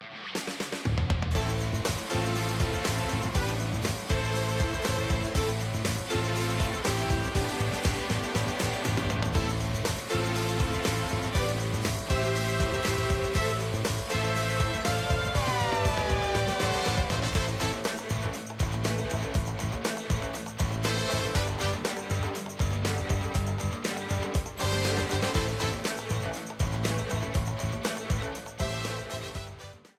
A battle theme
Ripped from the game
clipped to 30 seconds and applied fade-out